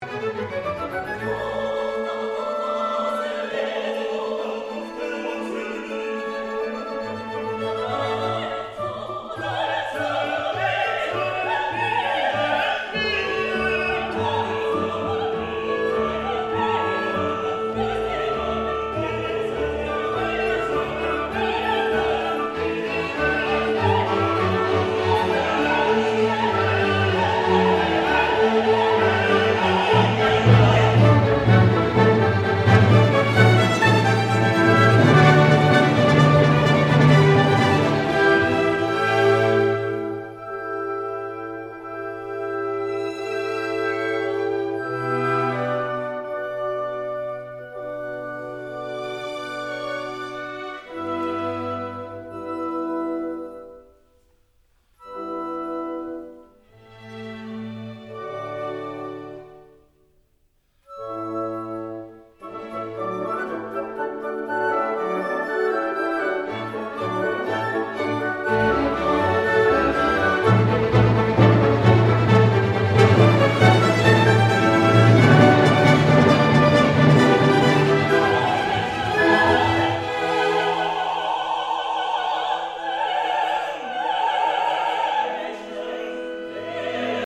04男性女性